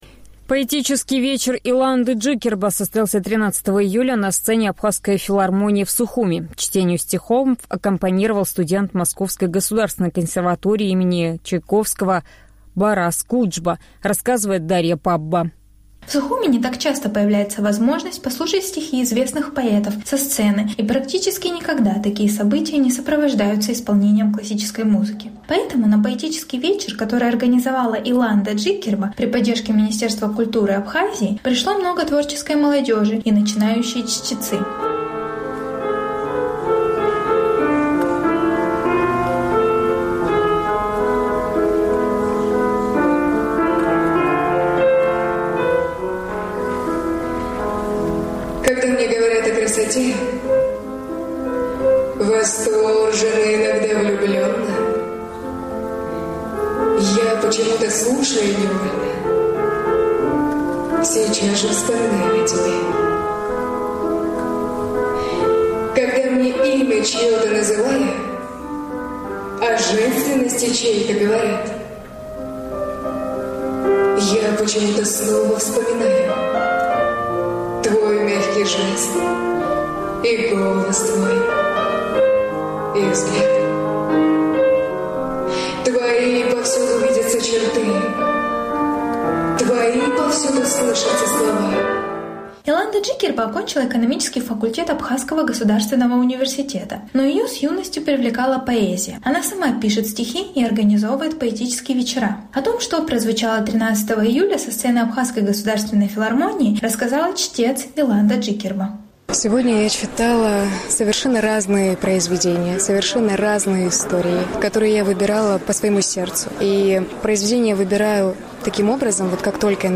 В Сухуме не так часто появляется возможность послушать стихи известных поэтов со сцены, и практически никогда такие события не сопровождаются исполнением классической музыки.
Знакомые зрителям стихи сопровождались звучанием романтических музыкальных композиций.